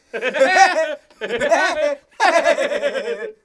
00417 laughter hehe synchro pretended
funny laughter man pretended sound effect free sound royalty free Funny